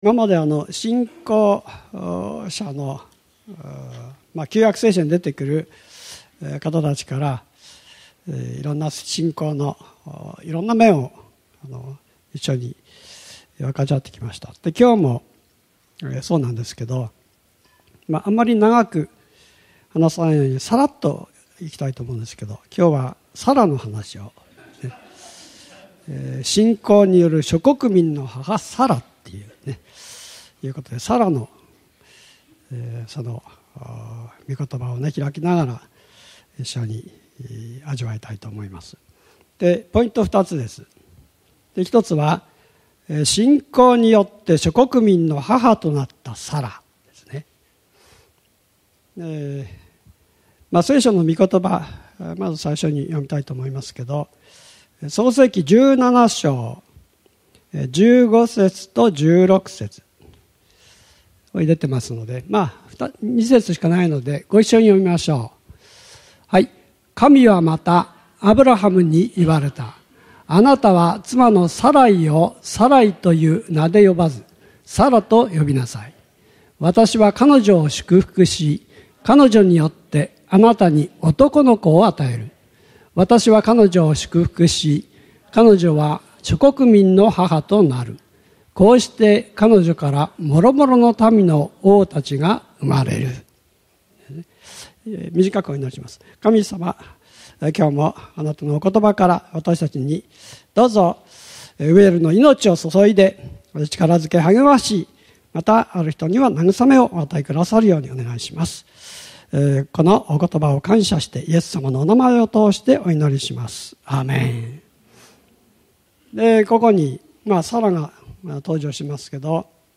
日曜礼拝